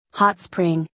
（ホットスプリング）